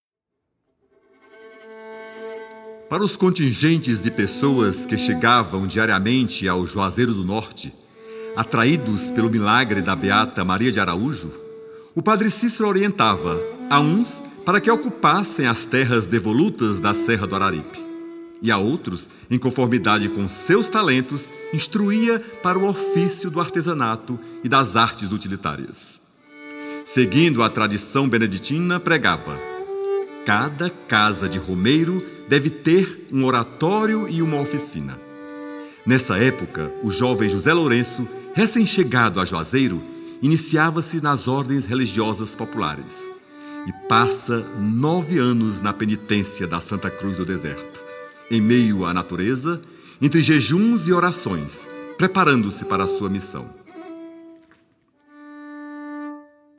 Gênero: Regional